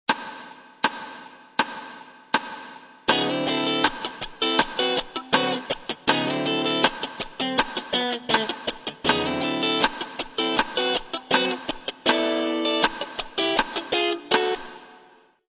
По сути, это усложненный вариант риффа из предыдущего занятия с добавлением двухголосных (дабл-стопы) и одноголосных линий.
Фанковый гитарный рифф
3slow.mp3